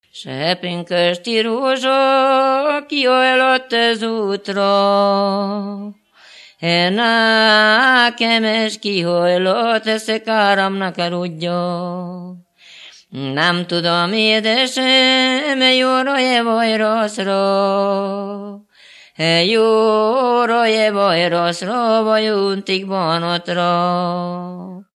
Moldva és Bukovina - Moldva - Klézse
ének
Műfaj: Keserves
Stílus: 7. Régies kisambitusú dallamok
Kadencia: 5 (2) 4 1